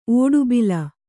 ♪ ōḍubila